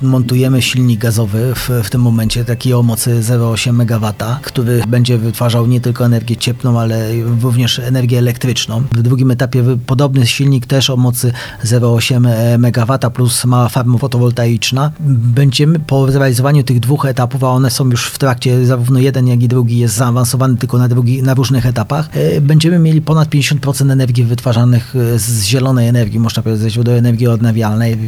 Prace prowadzone są w dwóch etapach, komentuje burmistrz Artur Ludew: